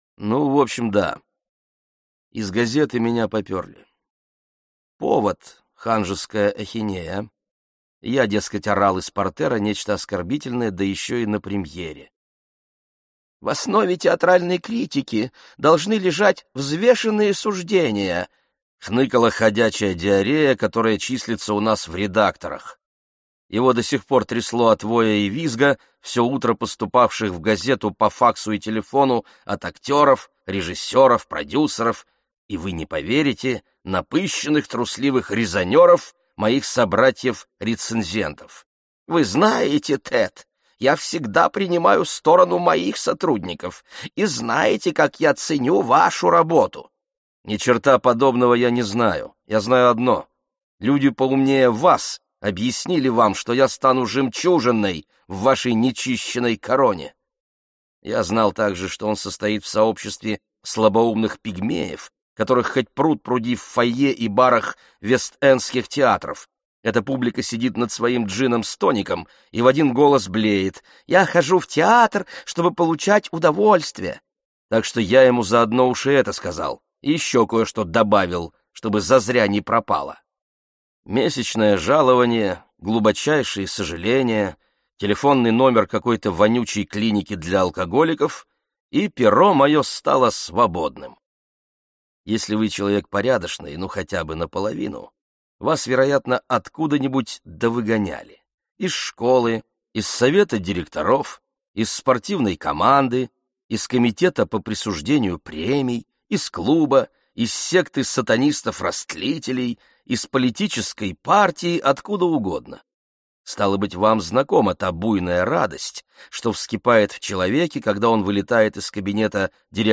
Аудиокнига Гиппопотам | Библиотека аудиокниг